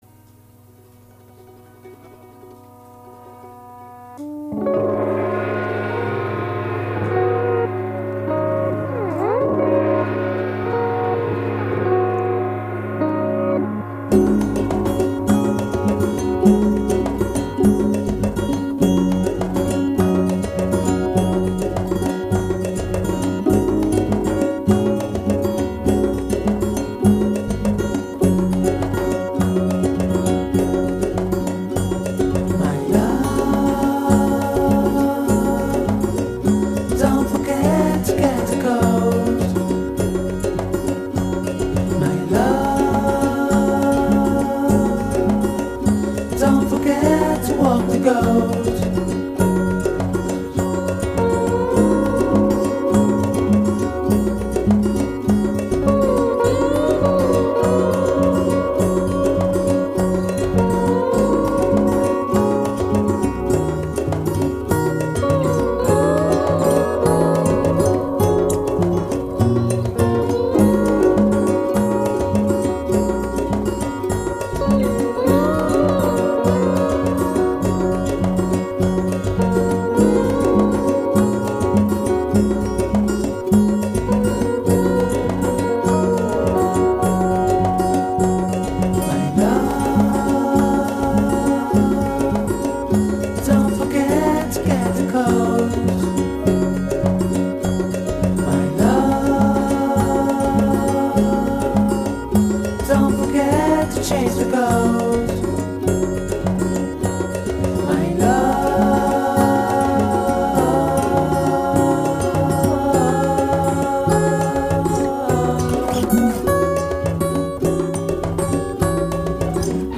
GenereRock